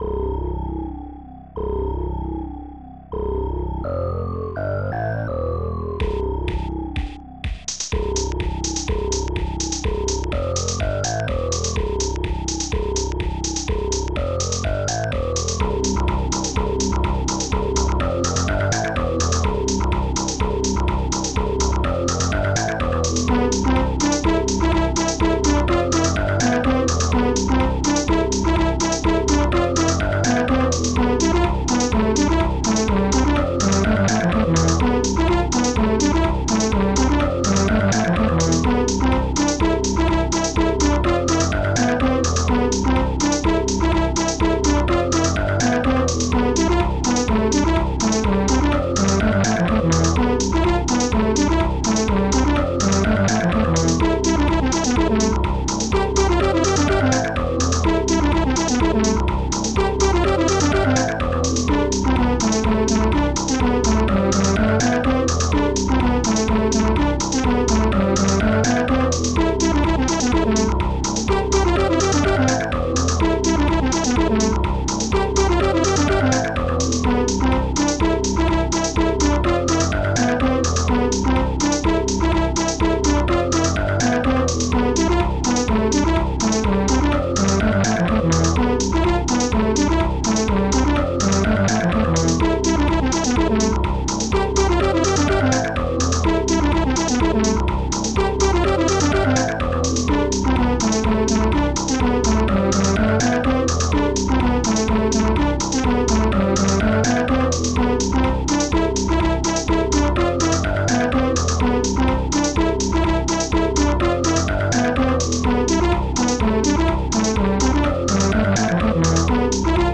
alien bassdrum2 growl hallbrass hihat2